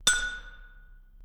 ting.mp3